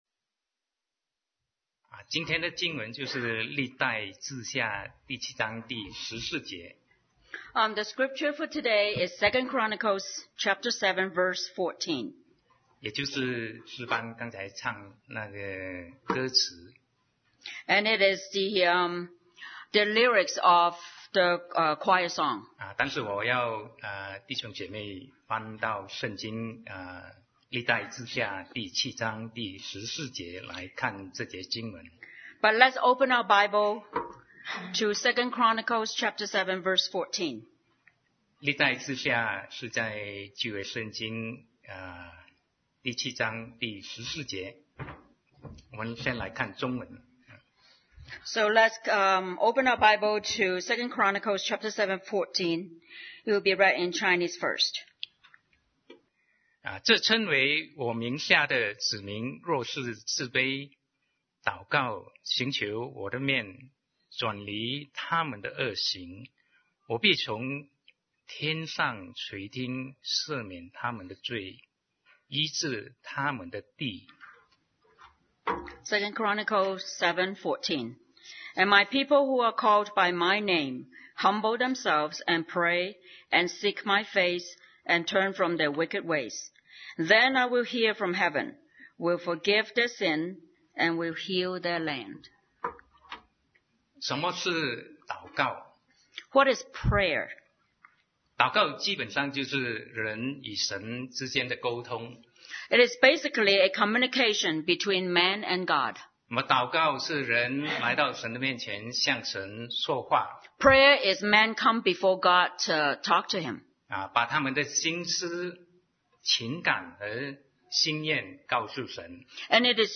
Sermon 2019-01-20 The Meaning of Prayer